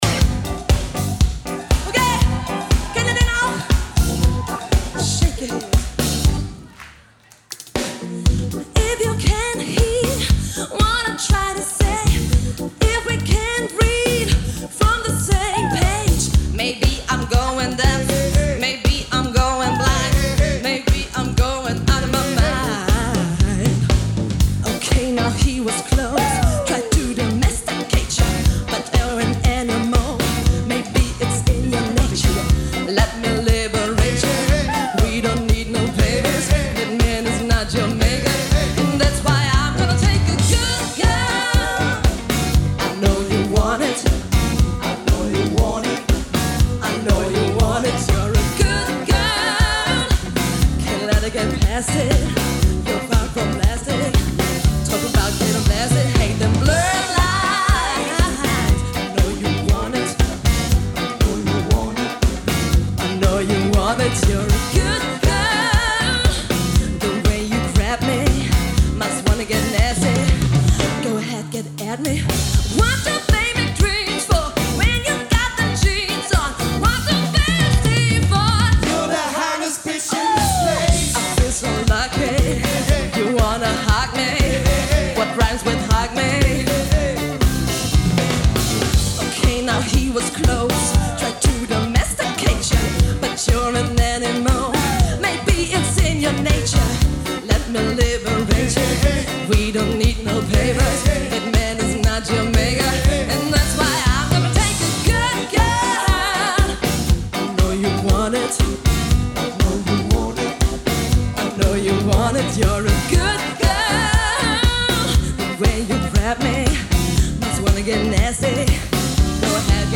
premium live music entertainment